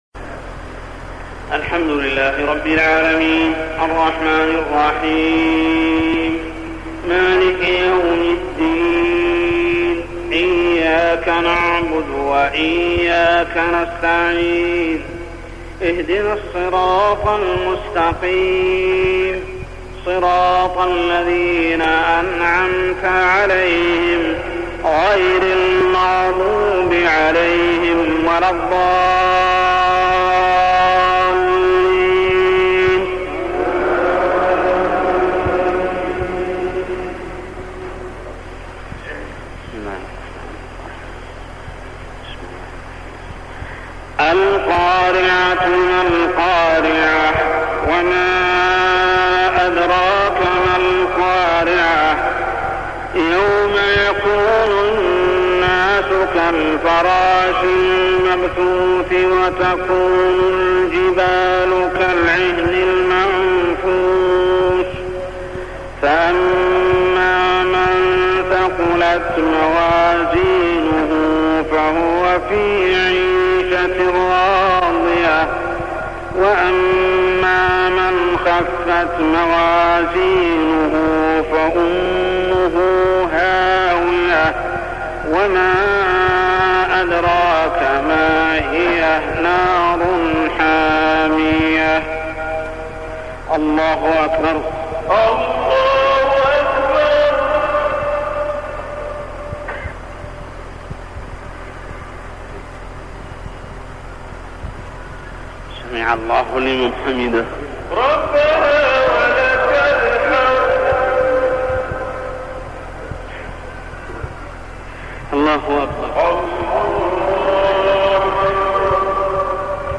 تلاوة من صلاة المغرب لسورتي القارعة و الماعون كاملة حدود عاميّ 1398هـ - 1399هـ | Maghrib prayer Surah Al-Qariah and Al-Ma'un > 1399 🕋 > الفروض - تلاوات الحرمين